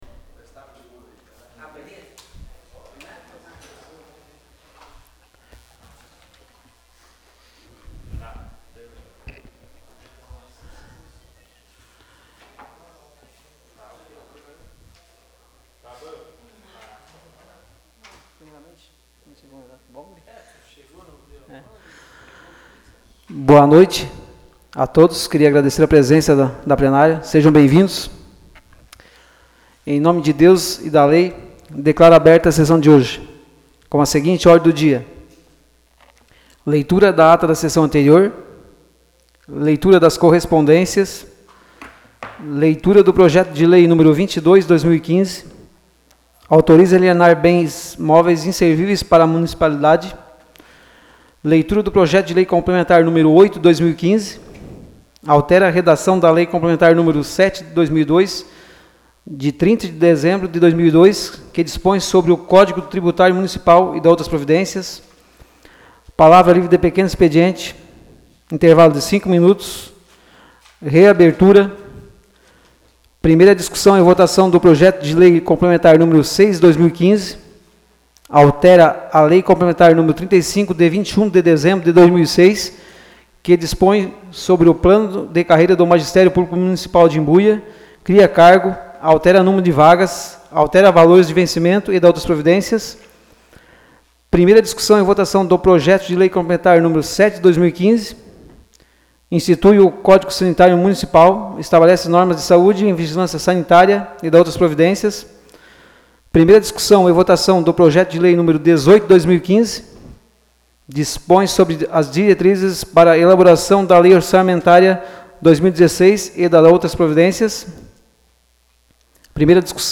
Áudio da Sessão Ordinária de 07 de dezembro de 2015